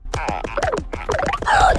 Arachno_Claw_2021_Roar.ogg